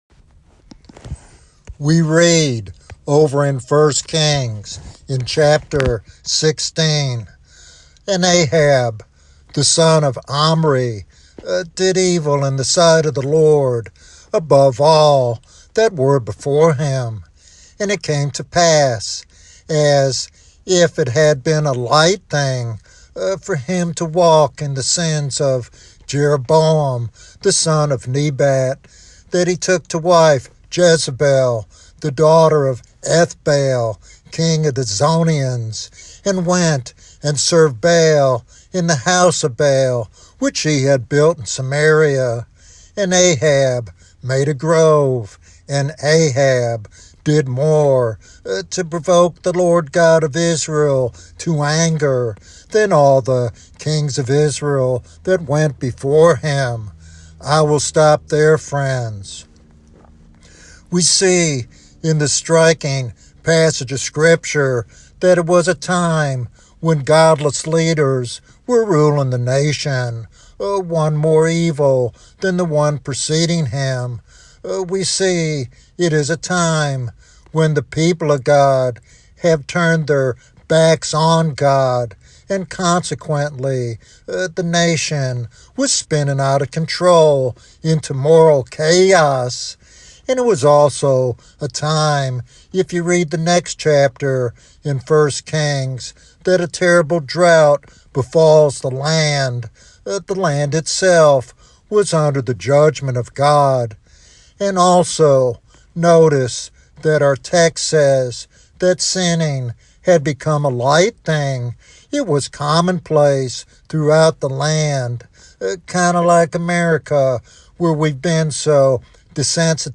This sermon challenges believers to recognize the urgency of repentance and revival in these troubled times.
Sermon Outline